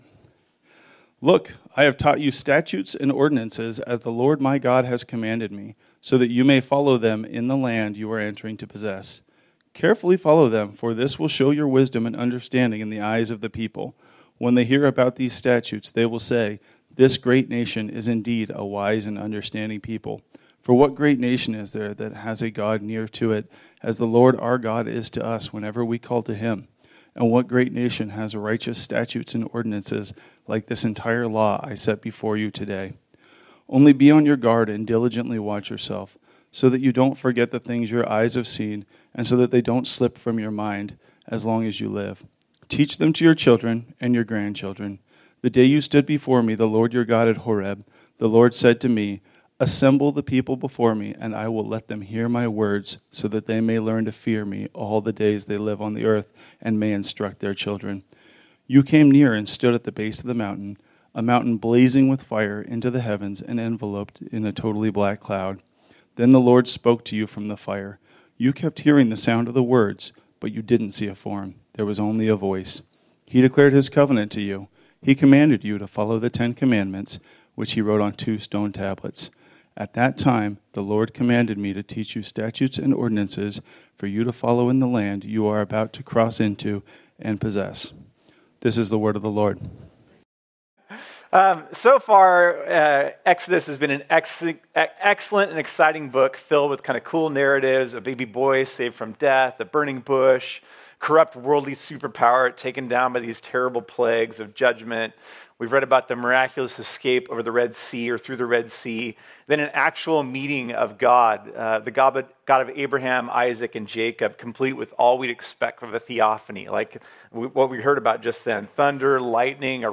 This sermon was originally preached on Sunday, March 30, 2025.